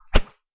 WHOOSH_Bright_mono.wav